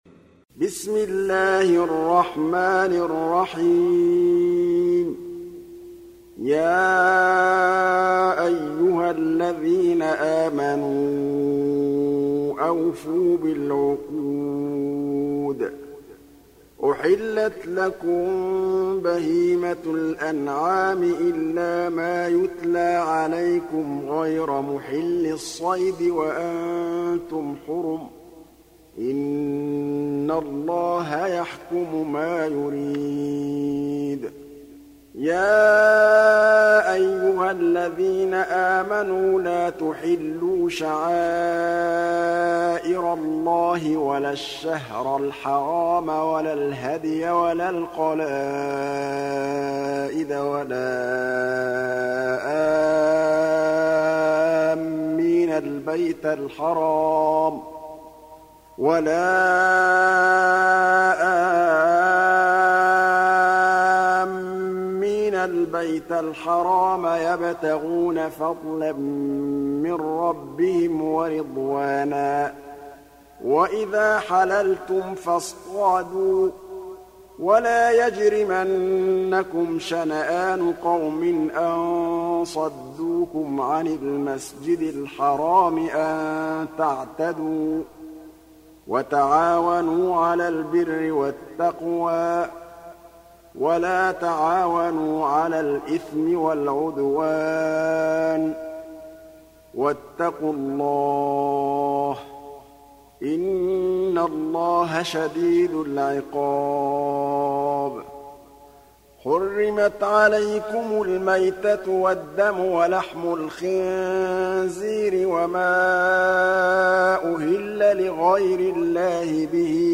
Surah Al Maidah Download mp3 Muhammad Mahmood Al Tablawi Riwayat Hafs from Asim, Download Quran and listen mp3 full direct links